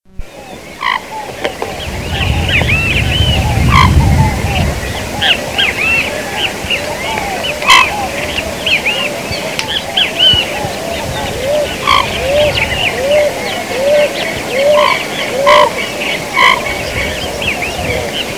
PATO CRESTUDO